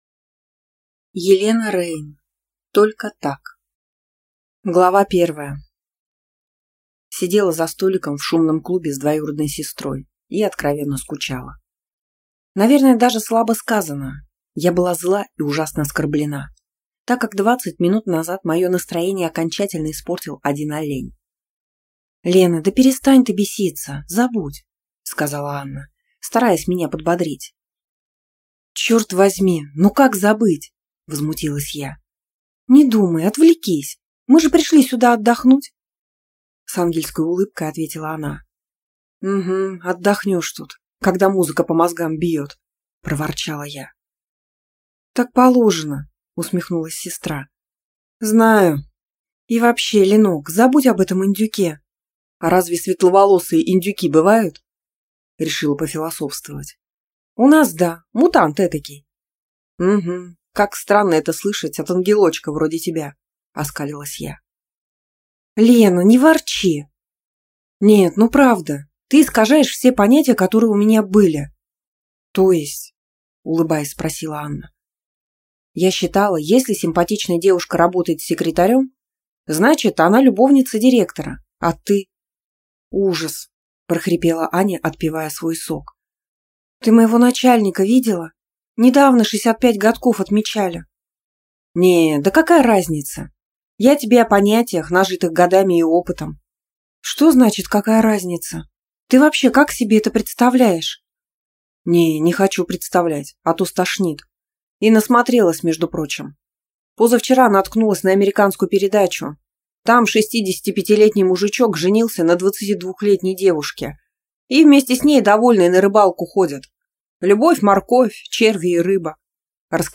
Аудиокнига Только так | Библиотека аудиокниг